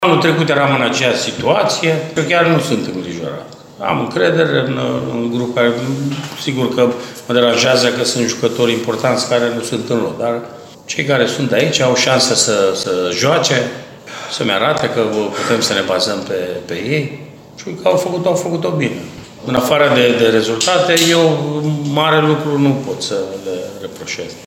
Cu toate acestea Rednic încearcă să evite, pe cât posibil, acumularea unei tensiuni suplimentare; în discursul său, tehnicianul face apel la memorie și dă exemplu perioada similară a anului trecut, când UTA era cam la fel în clasament: